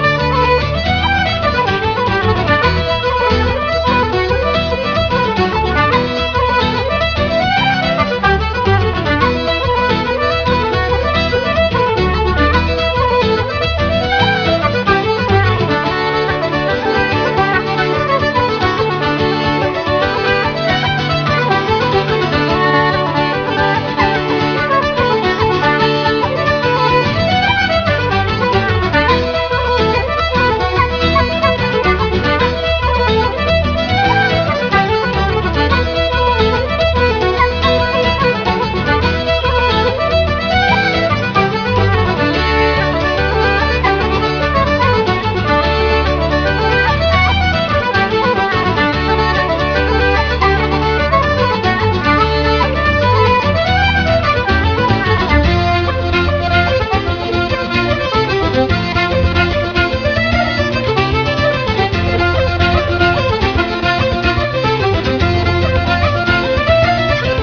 Contemporary/Traditional